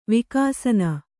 ♪ vikāsana